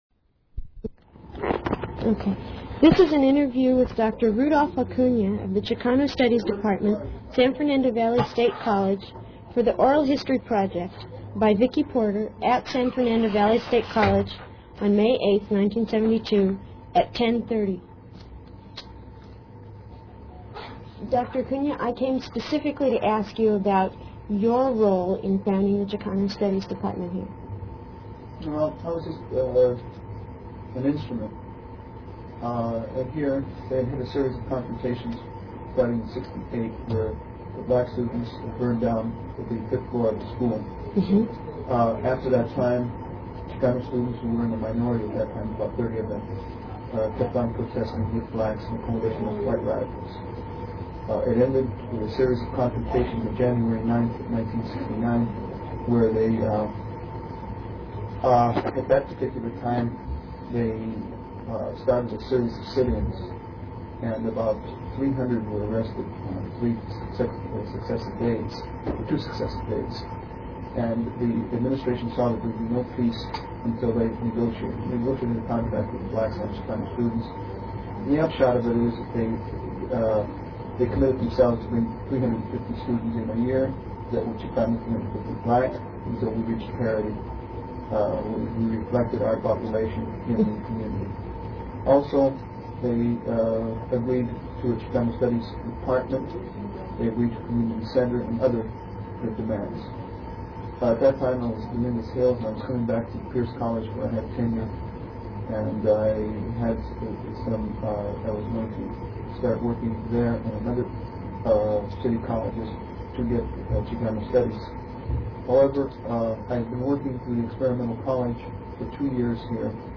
INTERVIEW DESCRIPTION - This short interview was conducted by a CSULB student. 5/8/1972